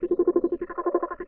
sci-fi_scan_target_05.wav